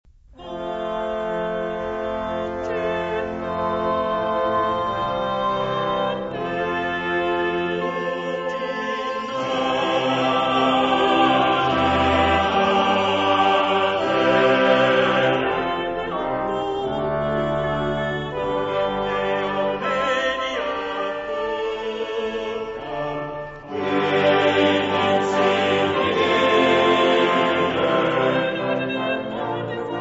• mottetti
• musica sacra
• Motet